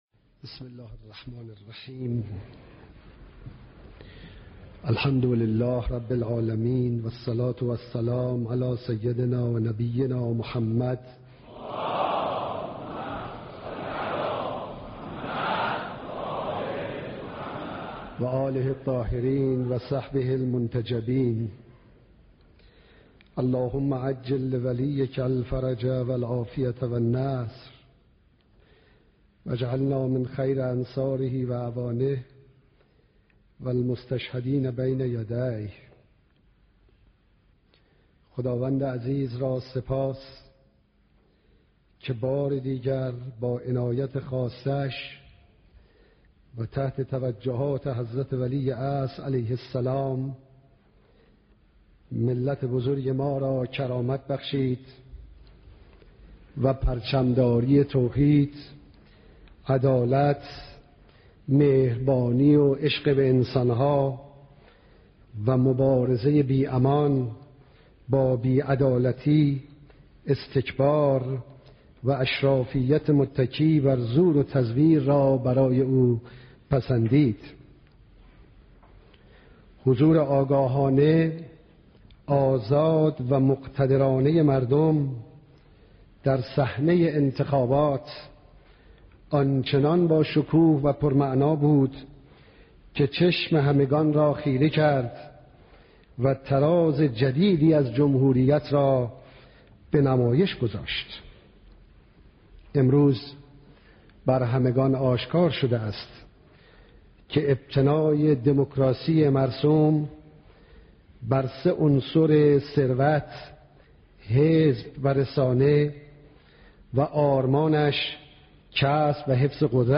صوت کامل بیانات
سخنان رئیس جمهور در مراسم تنفیذ